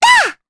Luna-Vox_Jump_jp.wav